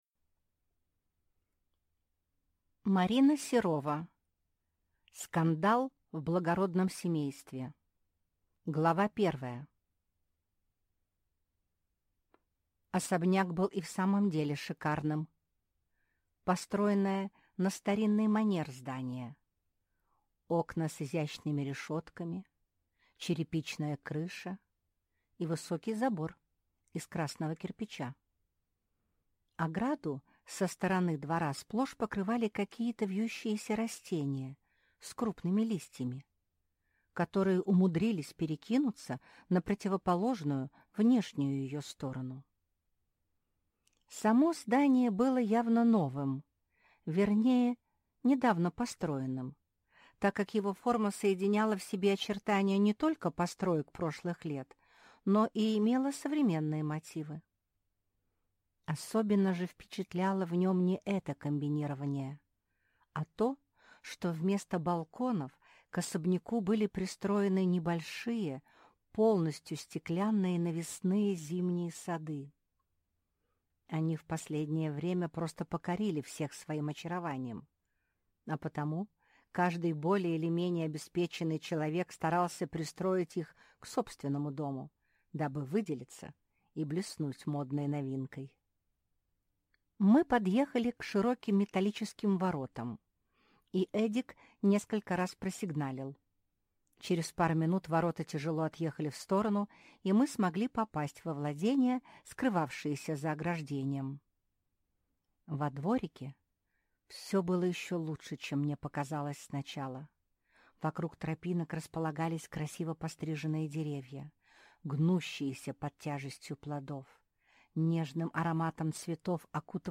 Аудиокнига Скандал в благородном семействе | Библиотека аудиокниг